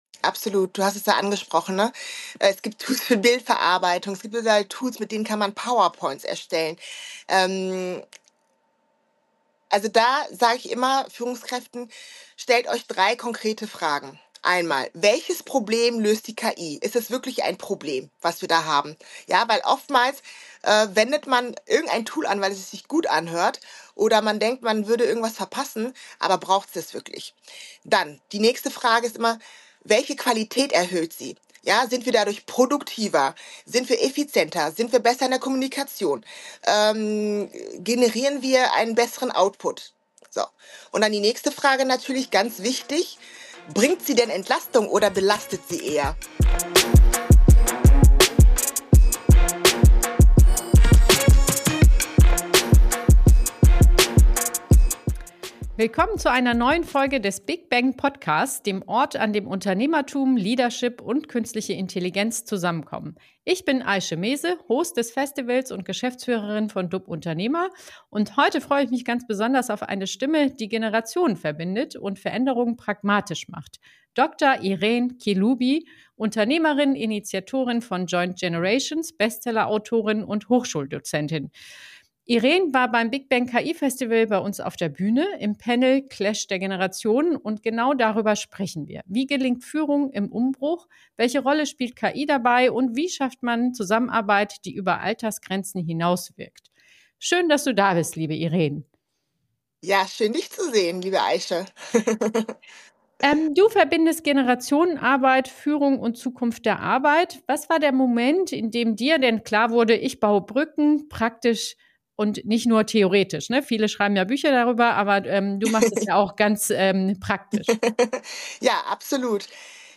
Ein Gespräch über einen Arbeitsrhythmus, der Menschen mitnimmt, Tempo ermöglicht und spürbar Wirkung zeigt.